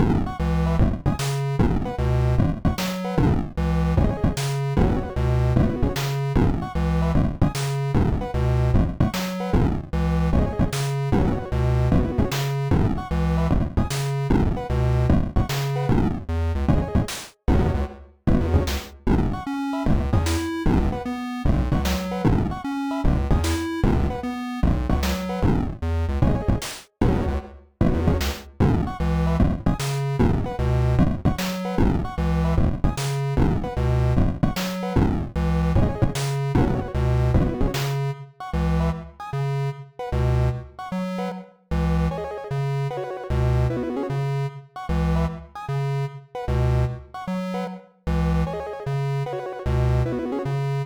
A slick little chip tune.